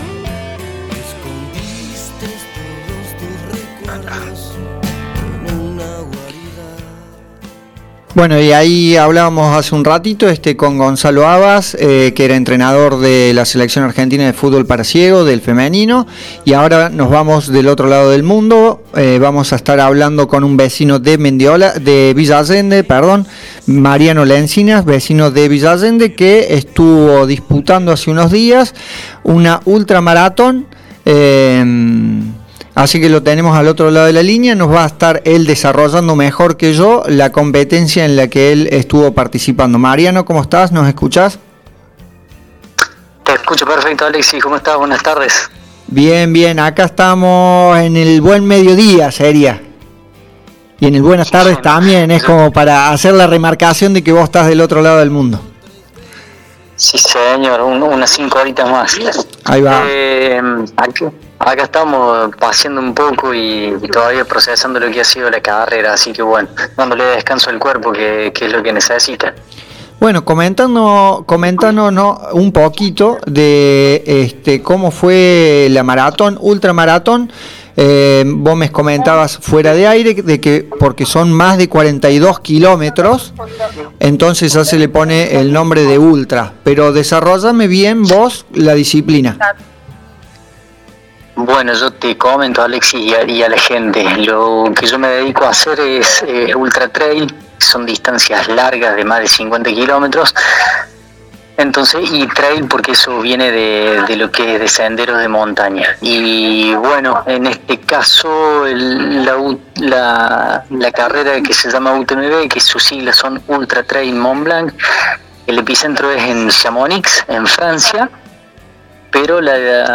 Este sábado tuvinos el placer de dialogar con el en Conexión Sport Sierras Chicas